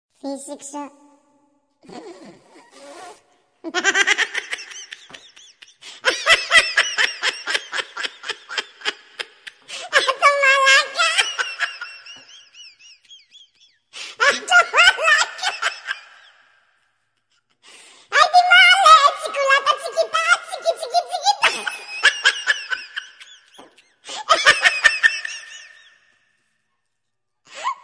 funny-sound-effect_24913.mp3